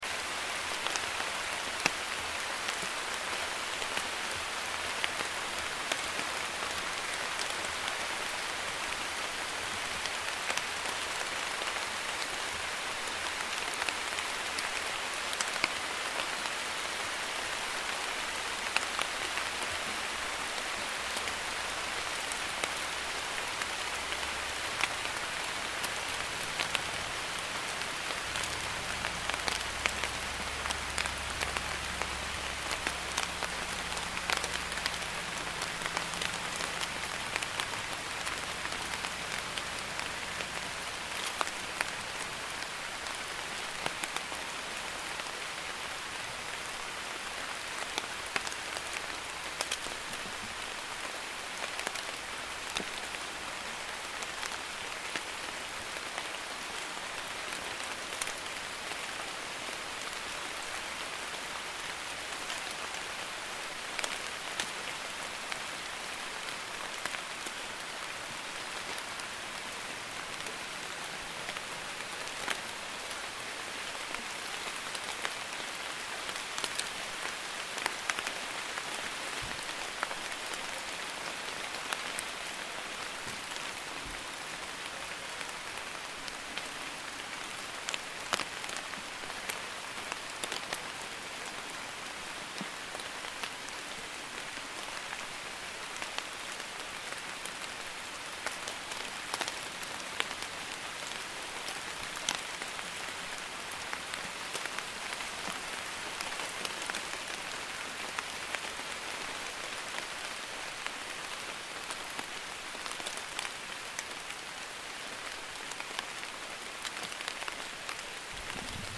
Thunder And Rain Sounds
Relaxing-rain-sound.mp3